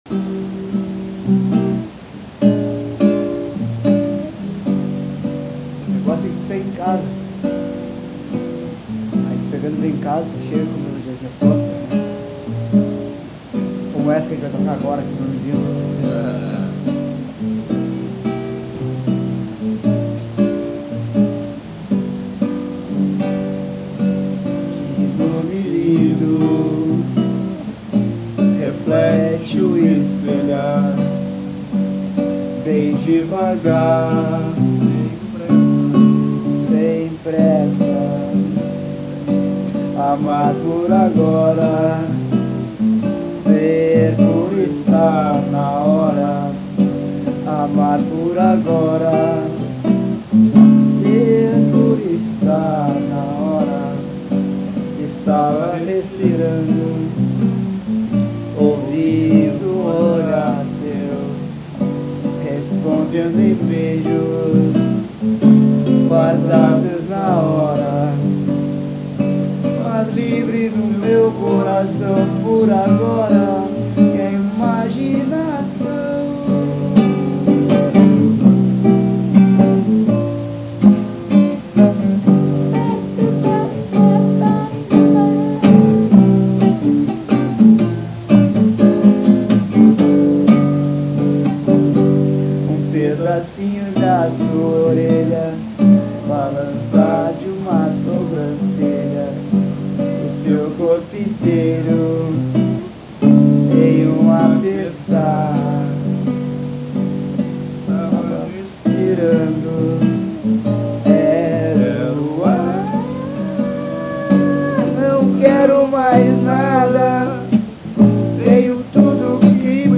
Musicas gravadas no momento de criação, realizadas no improviso, sem ensaio, sem estúdio ( faltam mais instrumentos em arranjos e ensaio, outras Letras melhores e mais importantes estão para terem acompanhamento de violão e ritmo, ou menos, ou mais, para virarem Novos Protótipos ou Esboços como base para Gravação )...